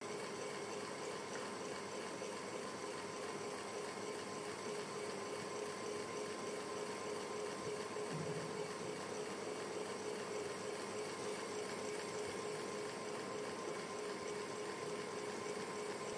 ceiling-fan-indoor.wav